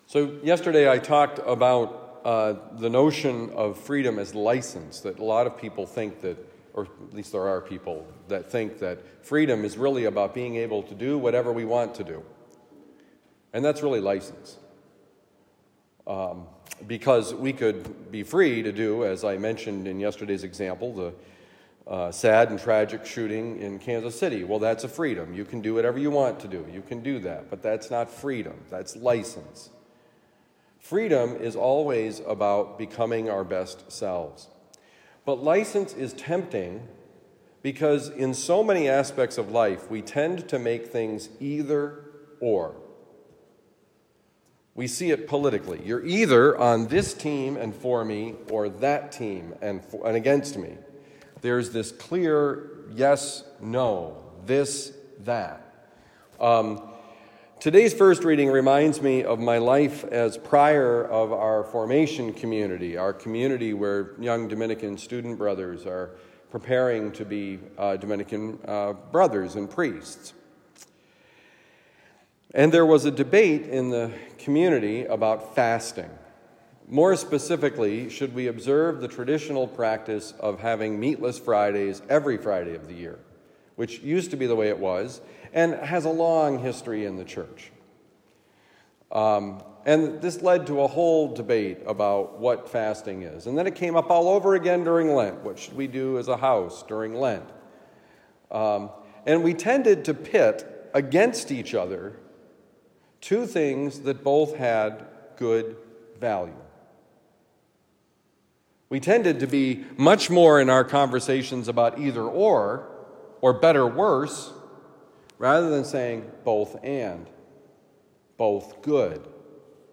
It’s not either or: Homily for Friday, February 16, 2024